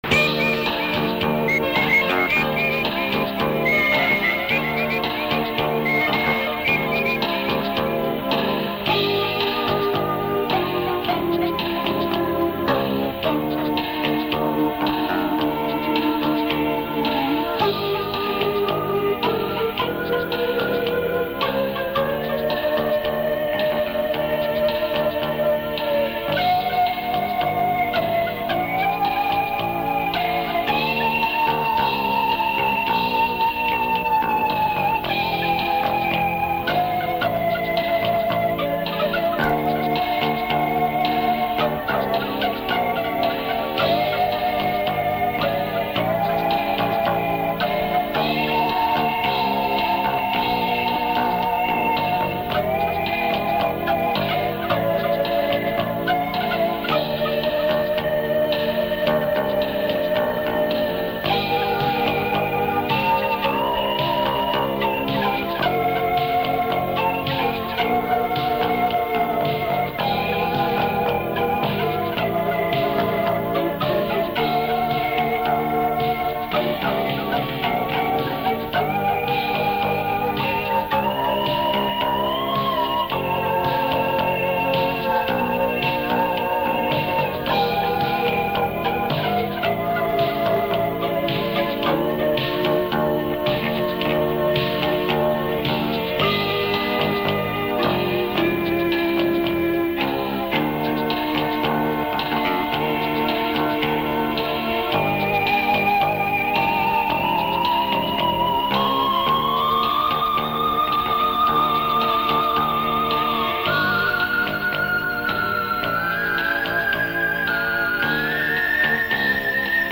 Запись 1991-92гг ....Звучат Roland D50, Yamaha YS200, Yamaha RX11 и бас-гитара....Очень чистый глубокий ревербератор, без хвостов, чисто глубина .......